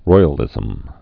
(roiə-lĭzəm)